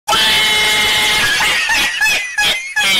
Gato Sound Effects MP3 Download Free - Quick Sounds